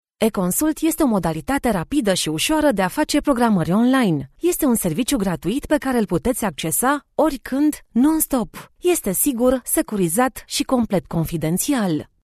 Female
Bright, Confident, Corporate, Friendly, Warm, Engaging, Natural
Neutral Romanian and English with Eastern European Accent
Microphone: Neumann TLM 107